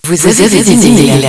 Ecouter l'écho